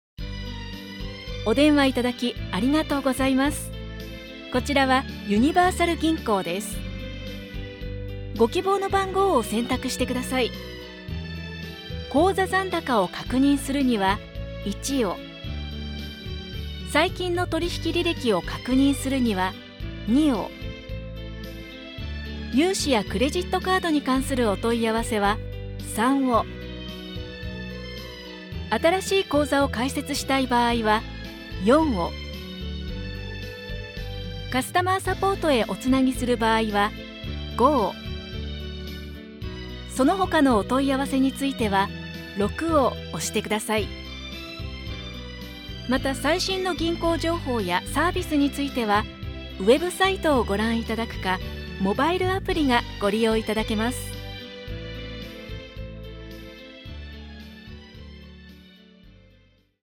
IVR